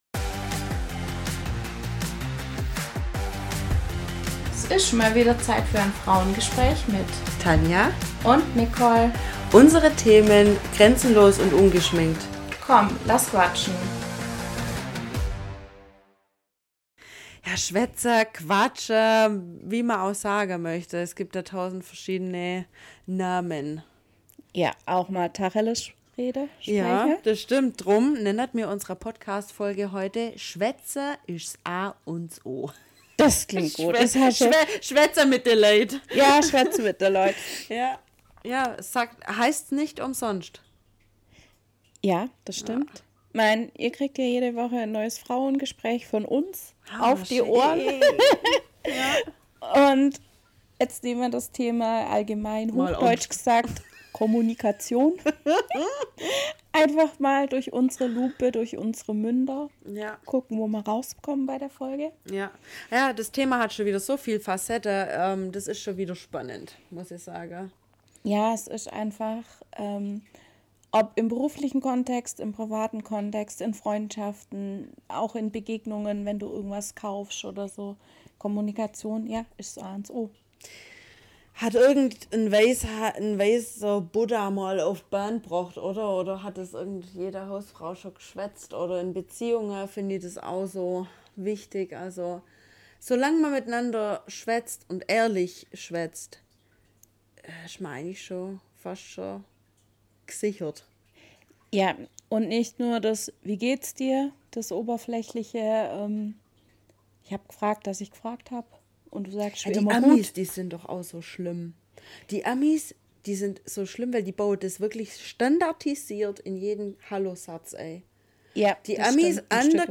Echte Kommunikation bedeutet, dass nach dem Gespräch mehr Verständnis, Nähe und Klarheit da sind - nicht nur leere Worte. Wir nehmen Euch mit und sprechen einfach mal drauf los, was wir rund um Kommunikation denke und welche Erfahrungen wir gemacht haben. Das alles haben wir Euch in eine Stunde Frauengespräch gepackt und wünschen Euch viel Freude beim Zuhören.